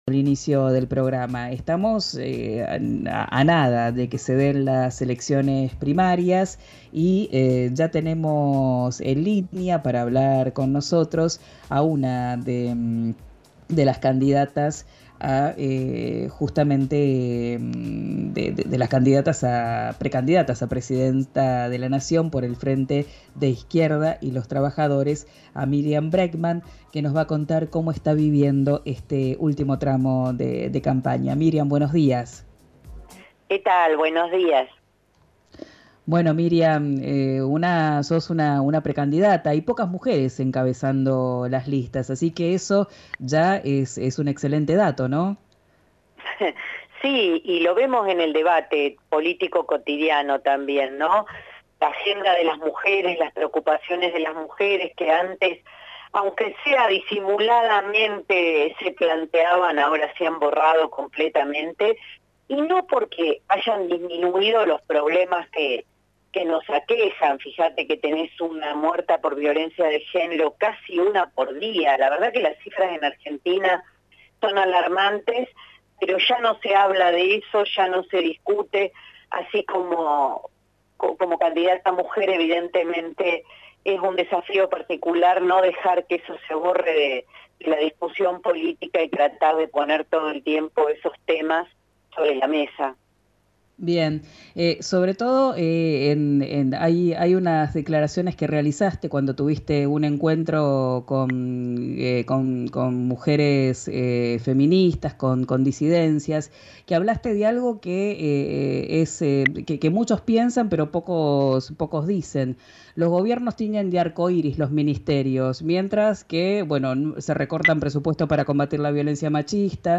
La precandidata a presidenta del Frente de Izquierda y los Trabajadores Unidad habló con RÍO NEGRO RADIO a una semana de las elecciones. Escuchá la entrevista completa.